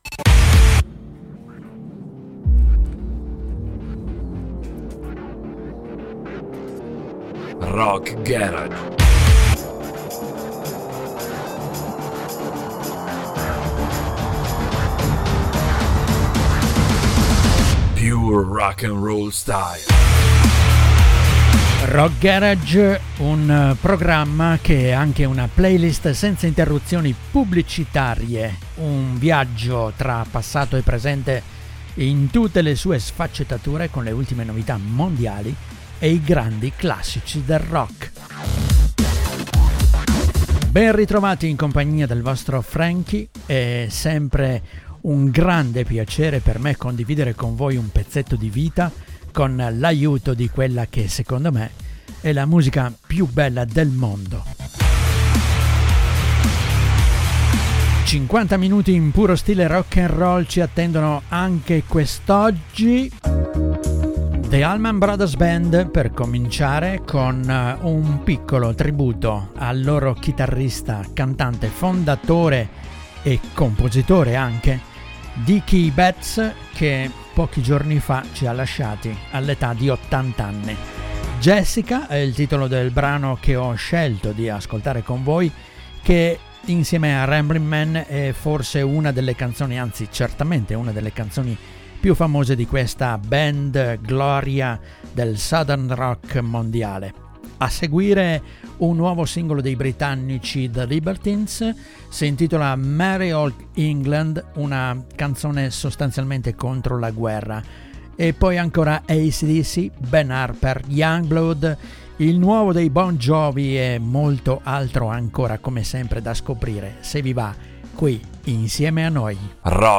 le ultime novità mondiali e i grandi classici del rock
è una compilation di brani senza interruzioni pubblicitarie.